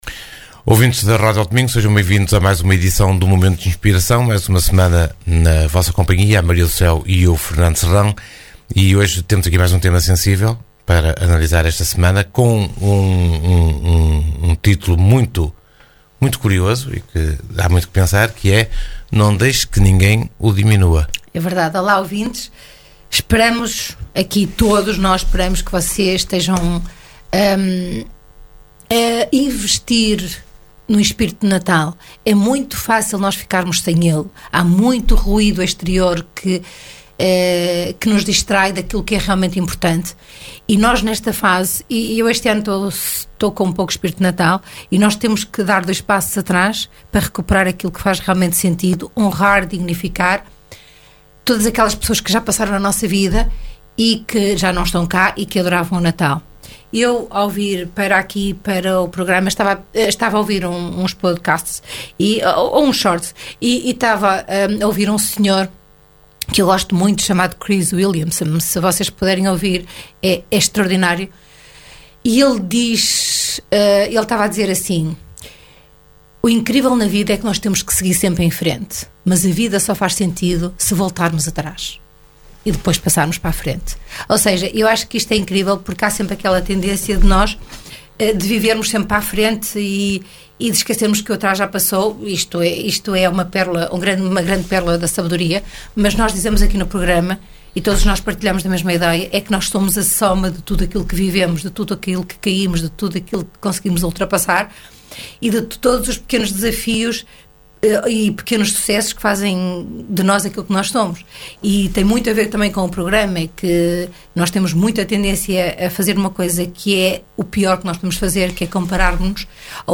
Momento de Inspiração (programa) | Segundas 22h.